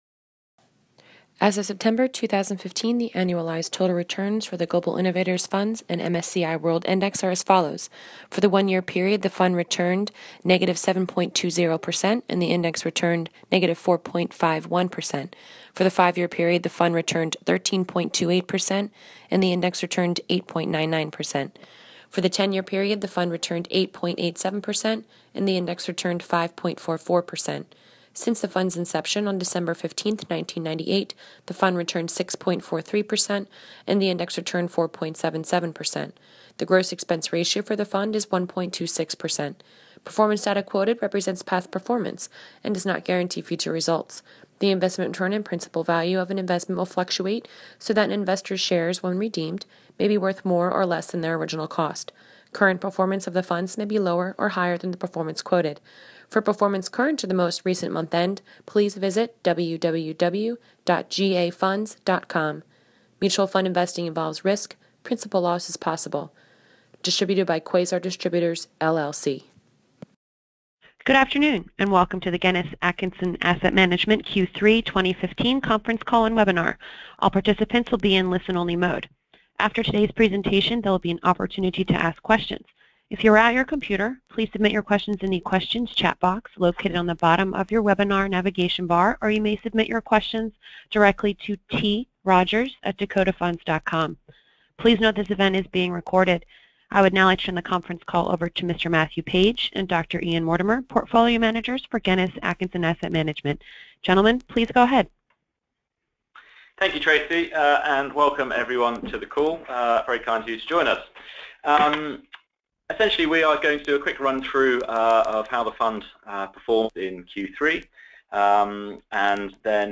GuinnessXAtkinsonXQ3X2015XConferenceXCallXandXWebinar.mp3